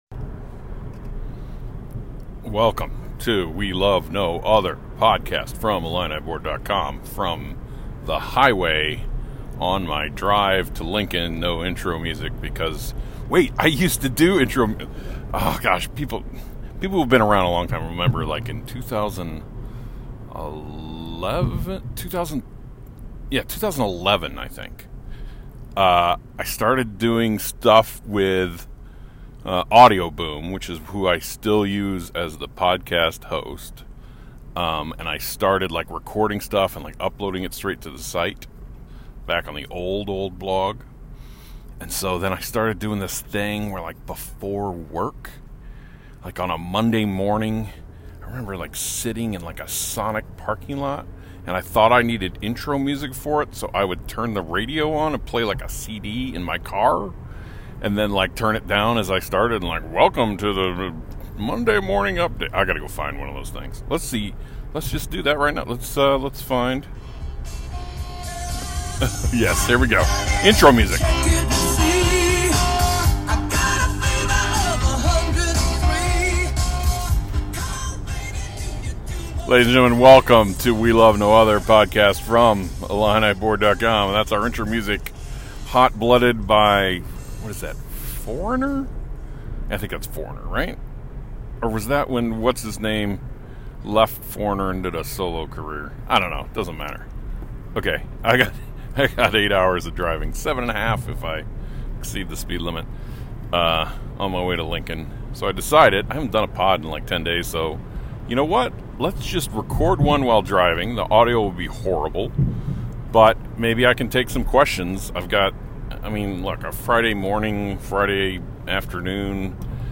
The audio is probably bad. It’s from a headset on the highway.